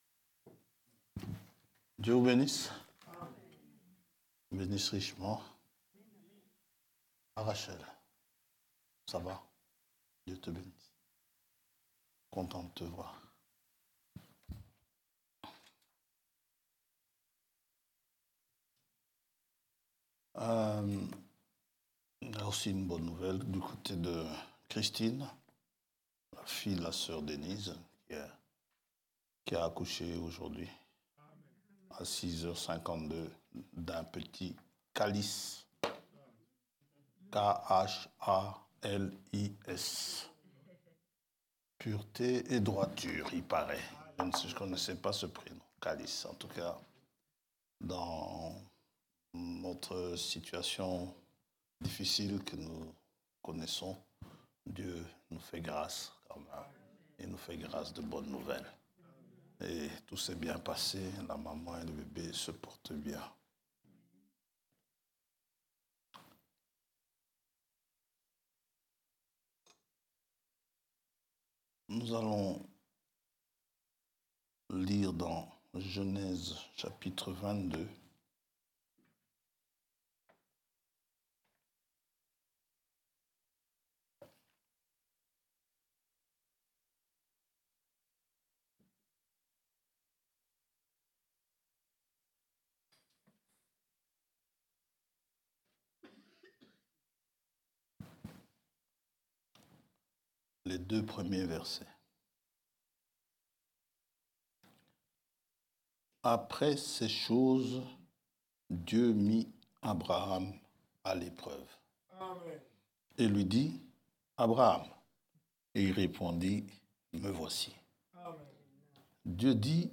Prédications